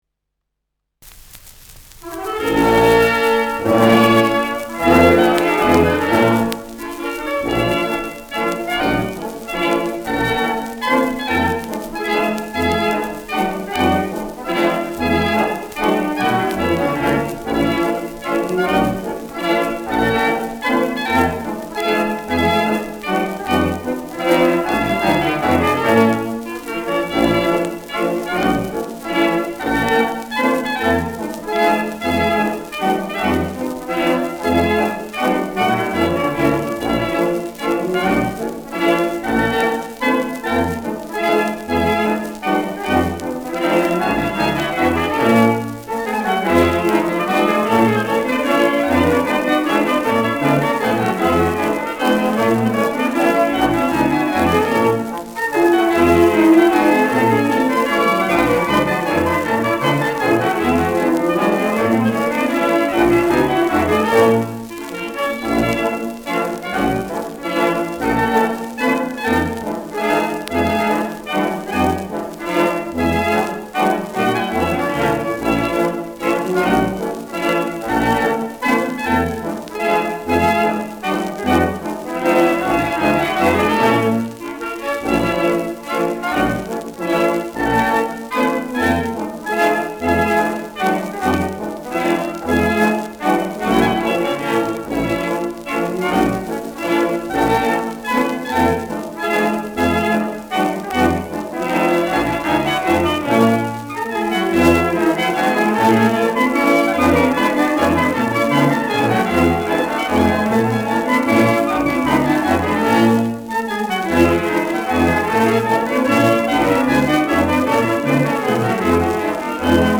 Schellackplatte
leichtes Rauschen : leichtes Knistern : vereinzeltes Knacken
Kapelle Almenrausch, München (Interpretation)
[München] (Aufnahmeort)